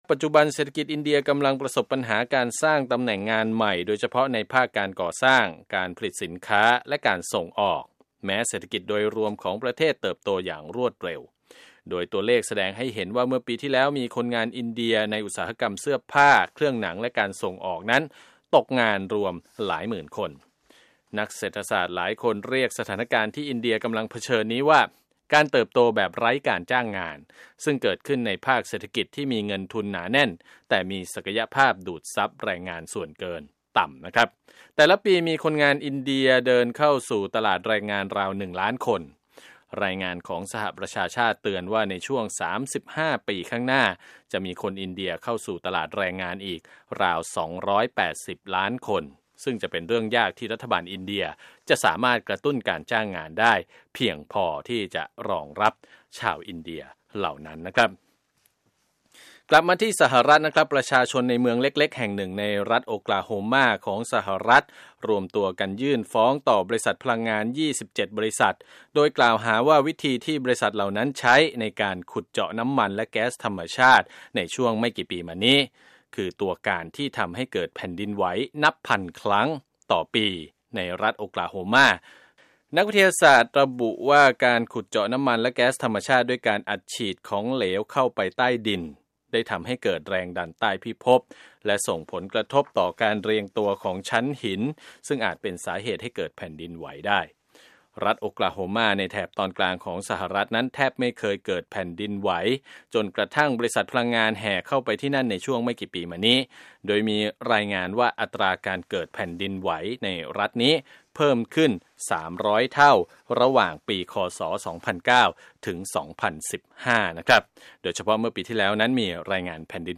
Business News